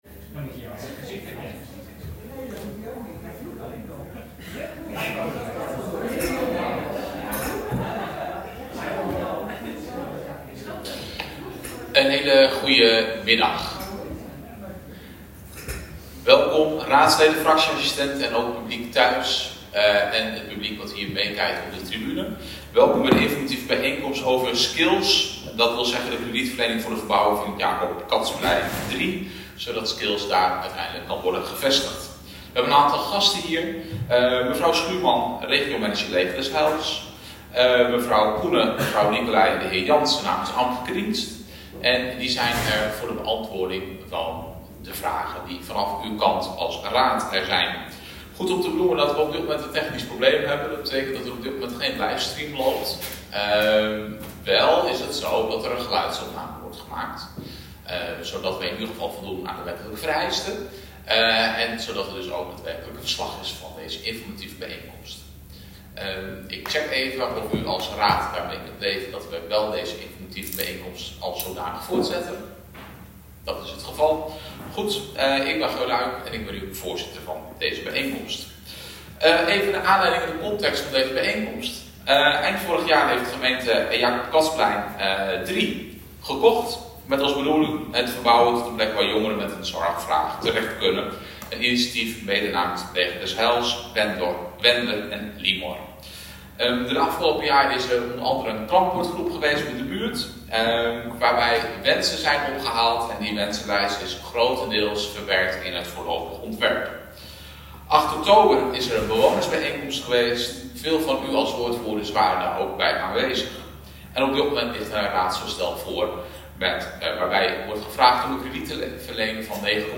Agenda Leeuwarden - Informatieve bijeenkomst - raadzaal Skills maandag 4 november 2024 16:30 - 18:00 - iBabs Publieksportaal
Wegens technische problemen is er geen beeldverslag van de bijeenkomst. Er is wel een geluidsopname gemaakt.